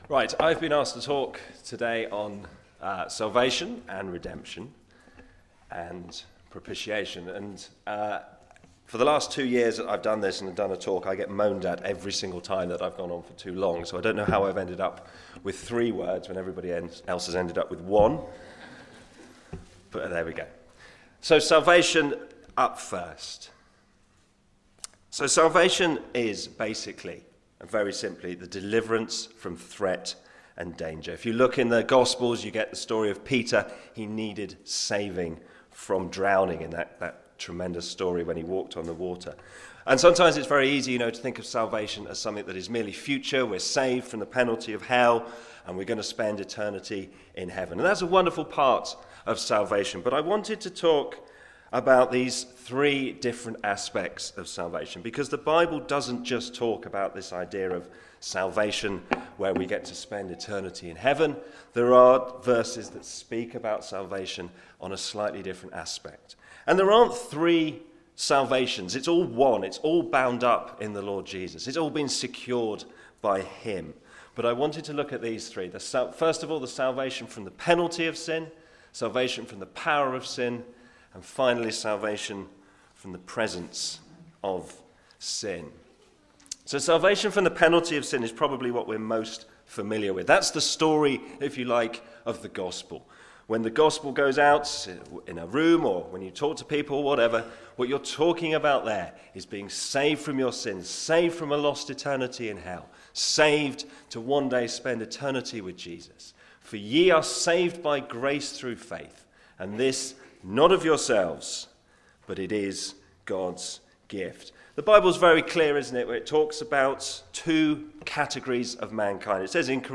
This talk at Refresh 2025 explored how Jesus’ sacrifice brings salvation, redeems us from sin, and satisfies God’s justice. It highlighted the depth of His grace and the freedom found in His redemption.